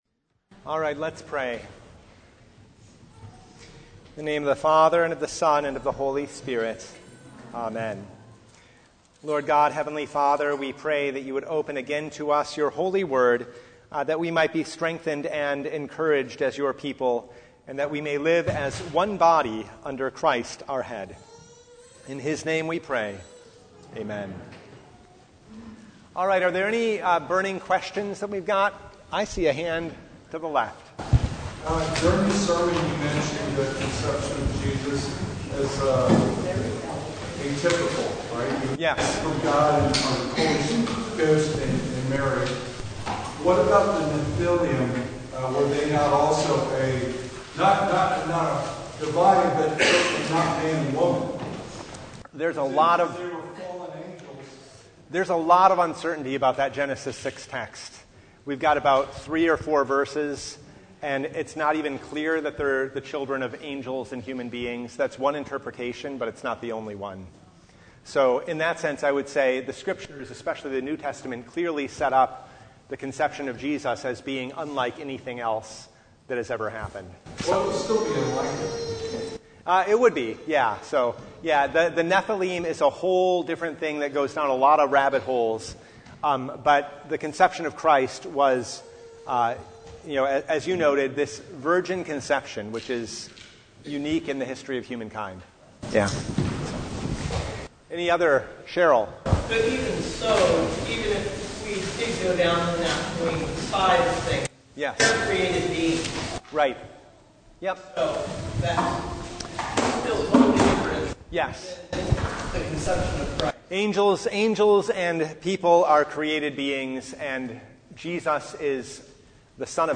1 Corinthians 12:12-31 Service Type: Bible Hour Topics: Bible Study « The Fourth Sunday in Advent